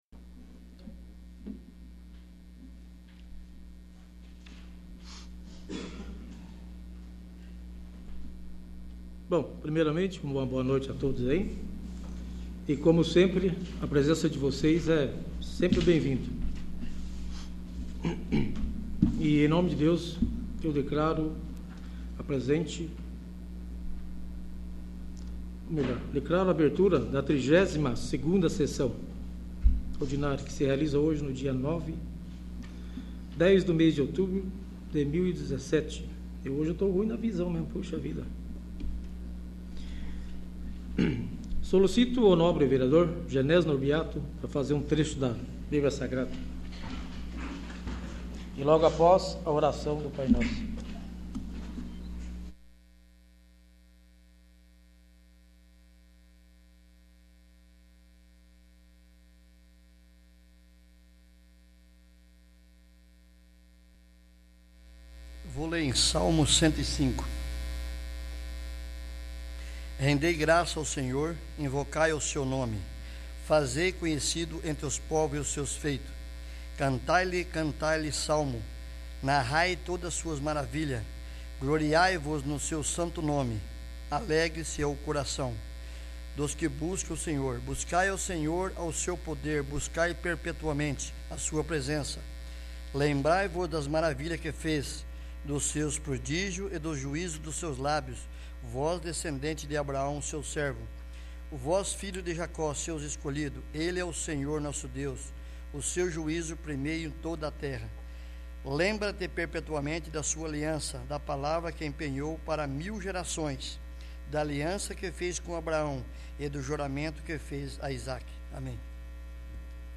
32º. Sessão Ordinária